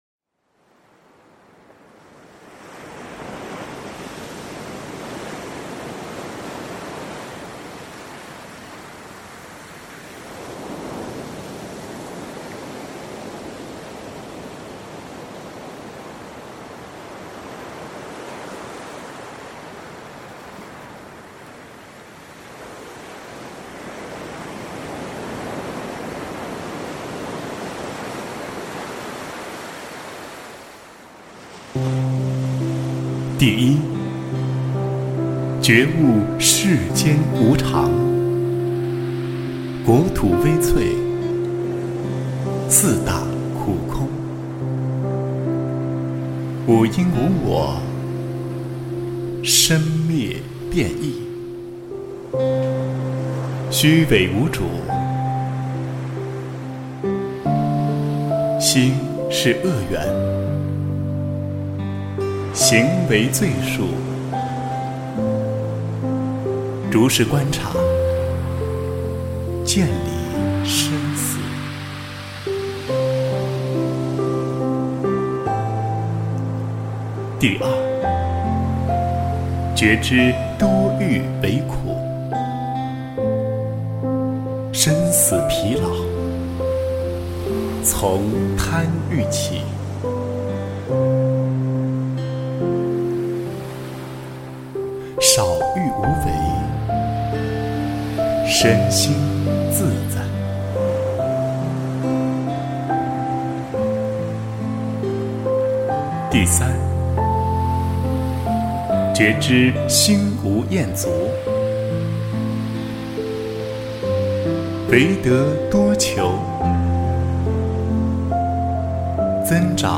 佛说八大人觉经（念诵）
佛音 诵经 佛教音乐 返回列表 上一篇： 吉祥经（读诵） 下一篇： 佛说十善业道经 相关文章 般若波罗蜜多心经--新韵传音 般若波罗蜜多心经--新韵传音...